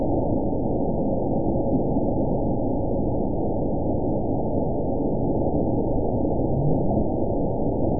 event 922677 date 03/06/25 time 20:57:04 GMT (3 months, 1 week ago) score 9.19 location TSS-AB02 detected by nrw target species NRW annotations +NRW Spectrogram: Frequency (kHz) vs. Time (s) audio not available .wav